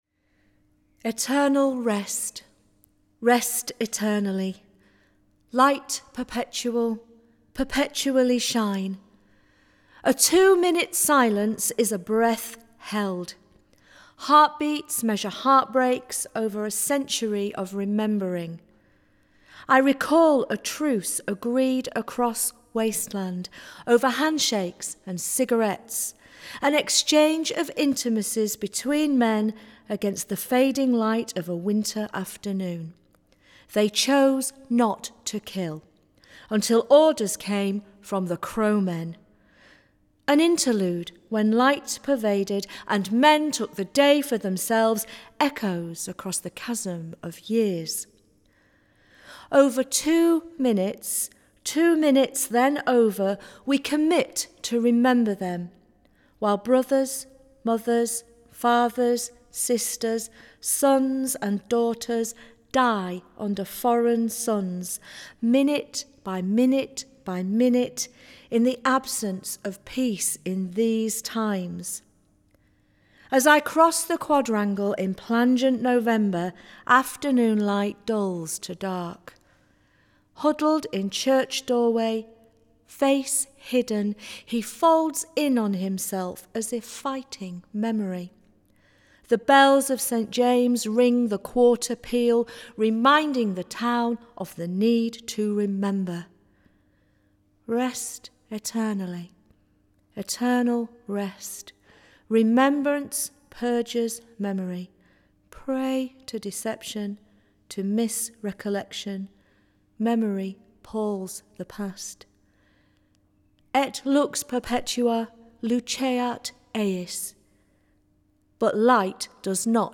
Recorded at Craxton Studios, May 12, 2019
Jazz and poetry commemorating the end of The First World War